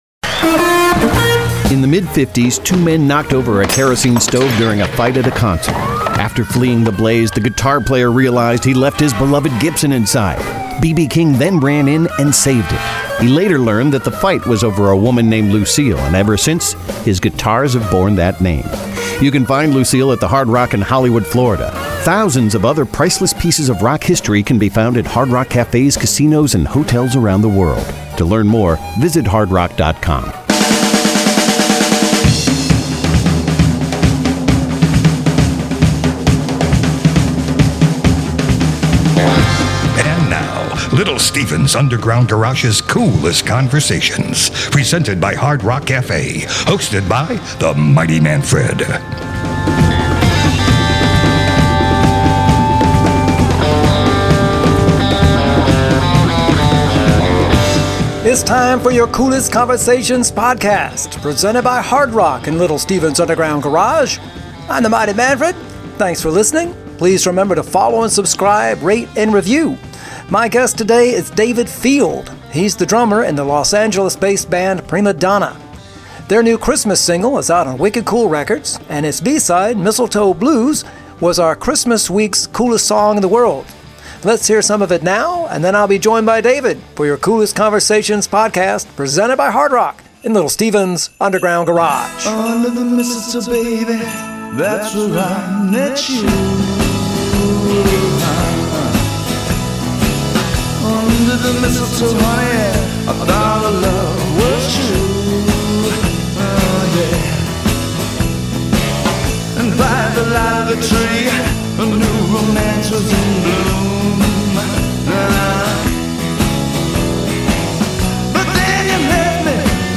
Coolest Conversation 12-25-20